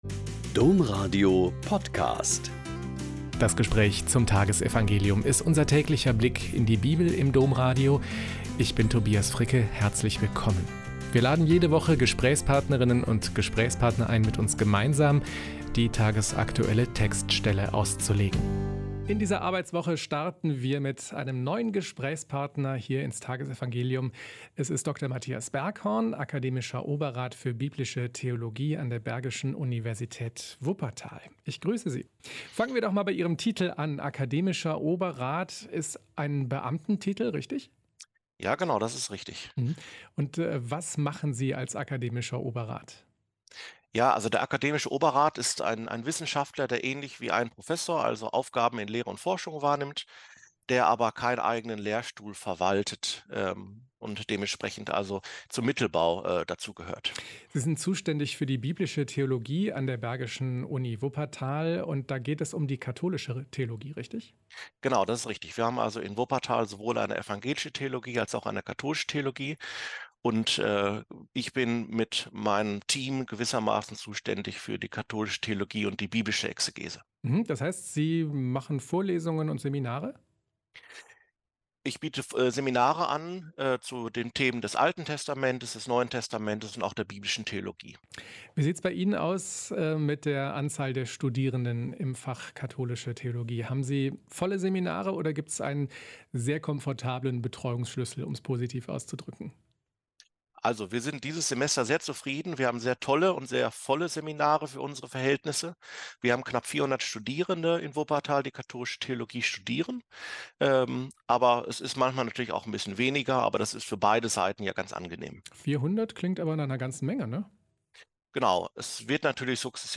Mt 9,18-26 - Gespräch